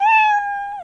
Meow Sound Effect Free Download
Meow